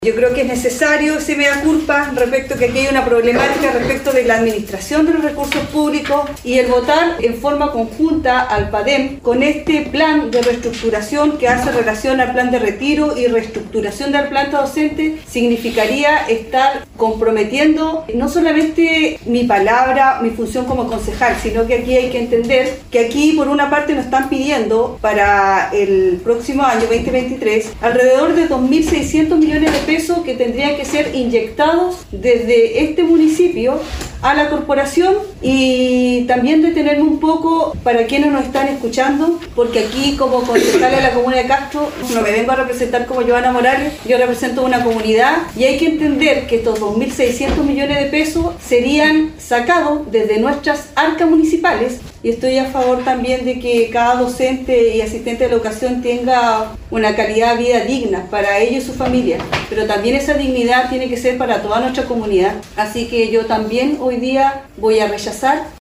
La Concejala Yohanna Morales, señaló que la inyección de recursos municipales para financiar el Padem es una cifra muy alta por lo que su voto fue de rechazo: